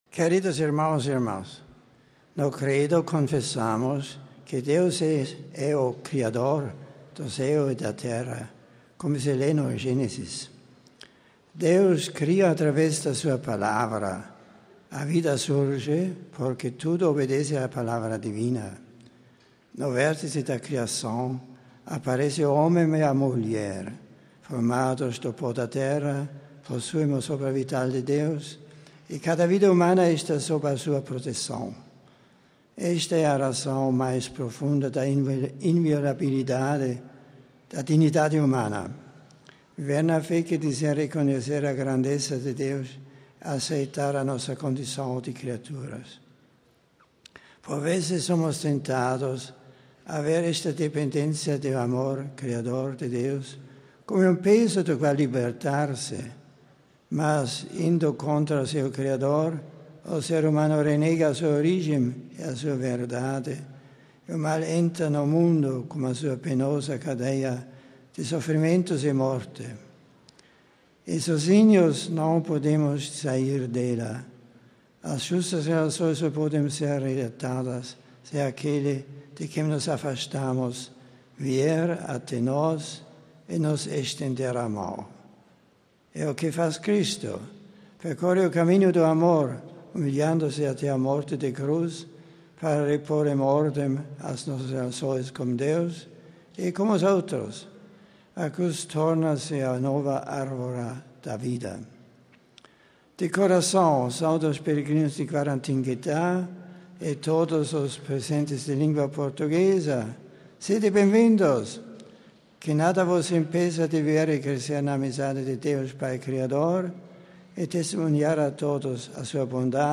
MP3 Cidade do Vaticano (RV) – Bento XVI recebeu milhares de fiéis e peregrinos para a tradicional Audiência Geral das quartas-feiras.
Ao final da saudação, o grupo brasileiro entoou para Bento XVI um hino a Nossa Senhora Aparecida.